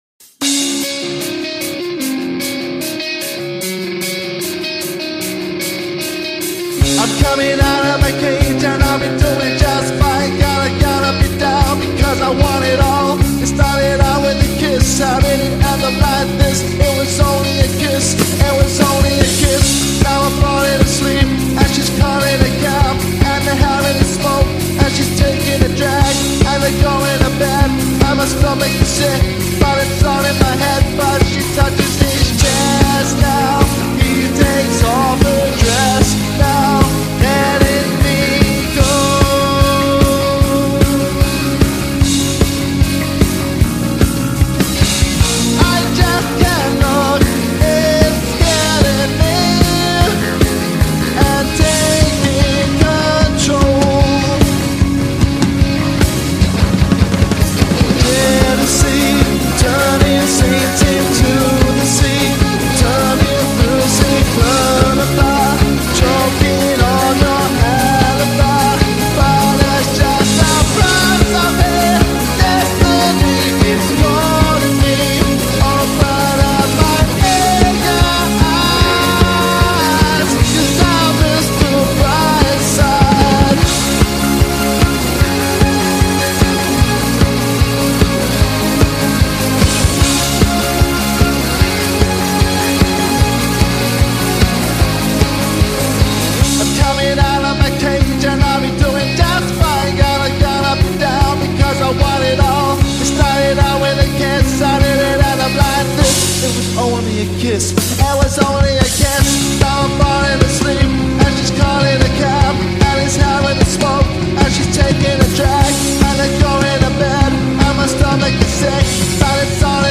Female Fronted 90s Tribute Band for Hire